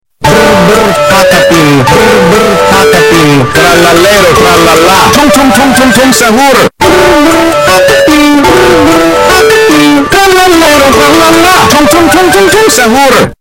Ultra Loud Italian Brainrot Ringtone Sound Effect Download: Instant Soundboard Button
Ultra Loud Italian Brainrot Ringtone Sound Button - Free Download & Play